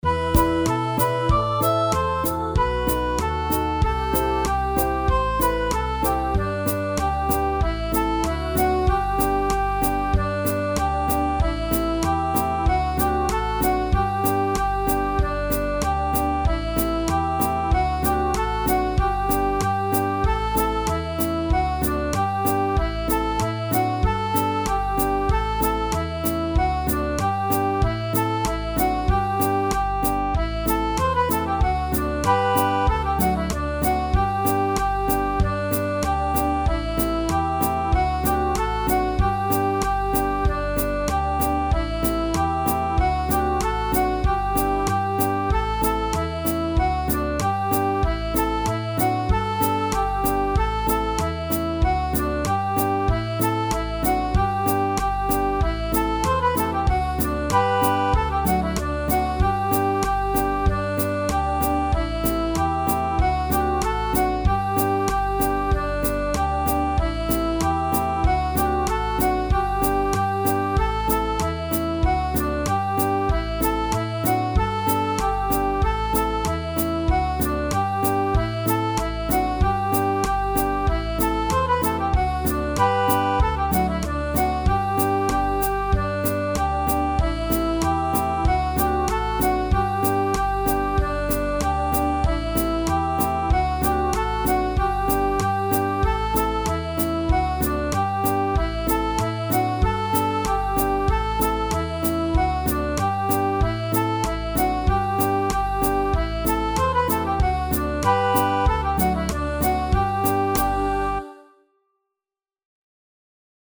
караоке
Скачать минус детской песни
минусовка